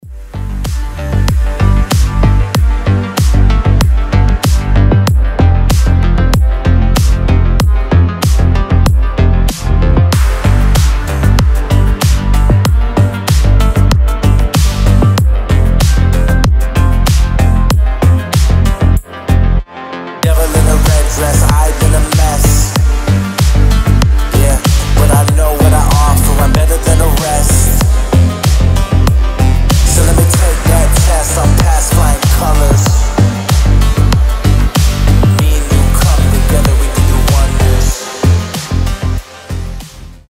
Клубные рингтоны
ритмичные клубные
без слов